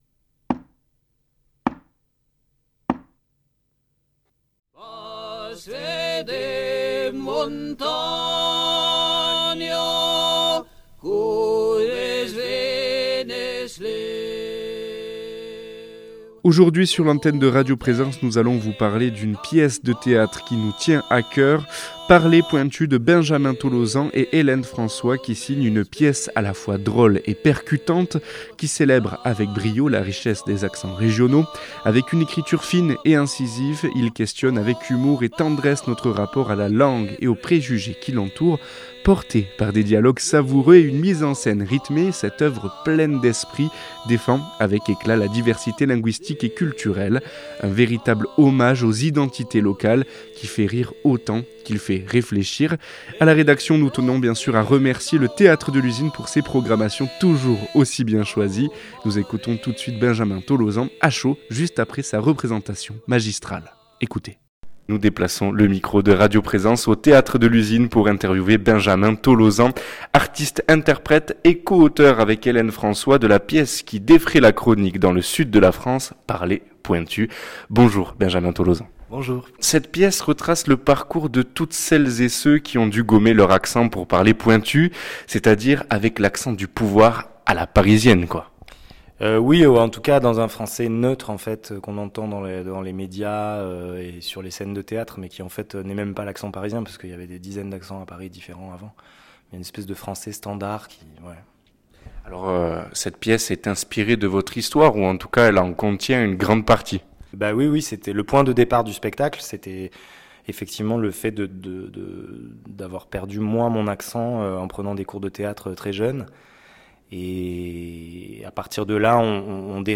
Entre récit personnel et analyse historique, porté par une création musicale évocatrice, ce podcast interroge la normalisation des accents et révèle les enjeux intimes et politiques d’une langue devenue norme.